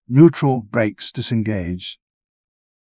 neutral-brakes-disengaged.wav